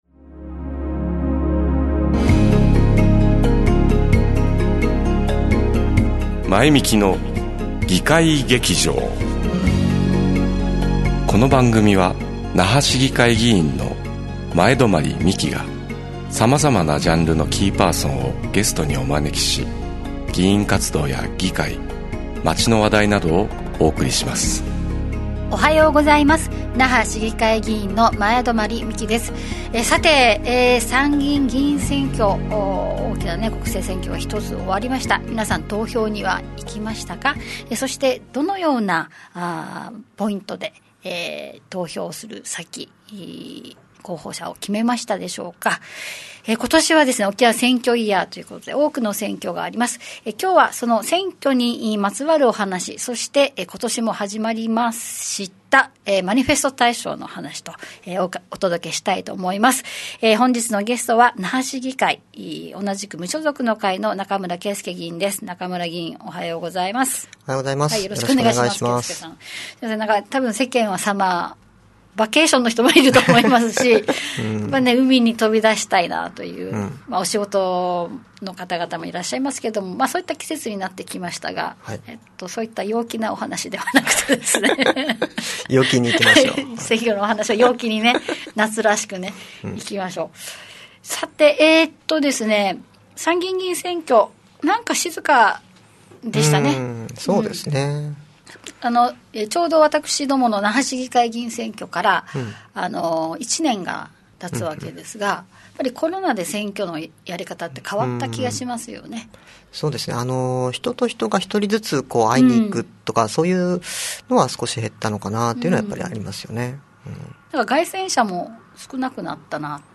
ゲスト:中村圭介/那覇市議会議員 〜 2022年の沖縄県は選挙イヤー。選挙の楽しみ方・面白がり方を考えよう